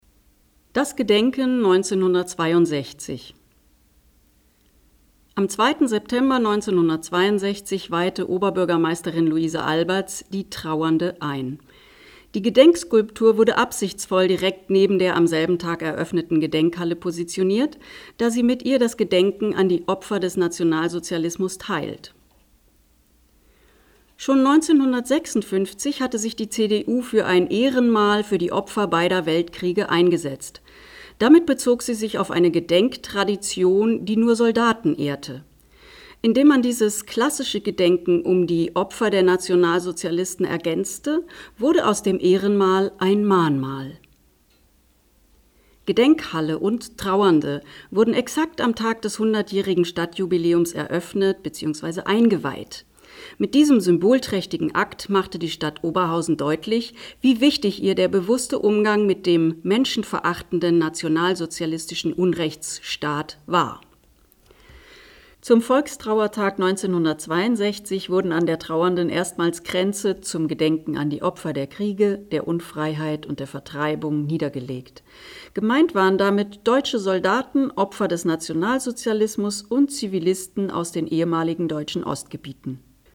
Tafeltext vorlesen